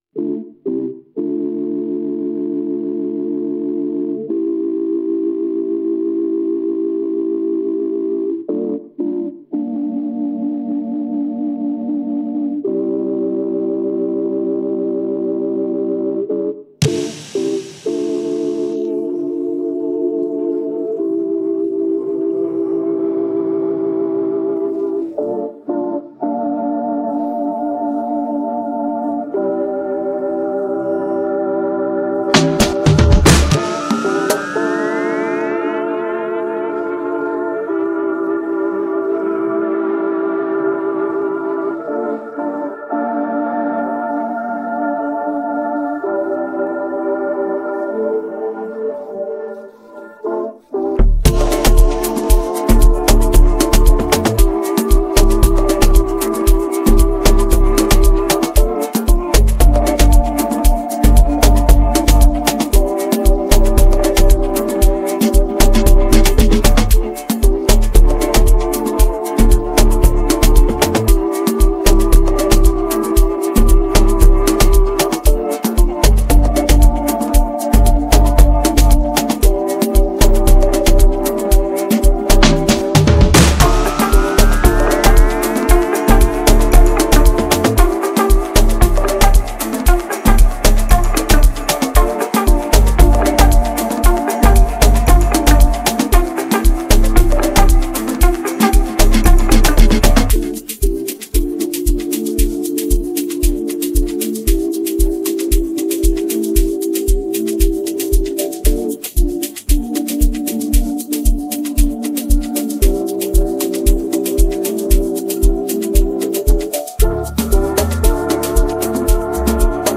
this beat has a pure afrobeat rhythm and hard sound.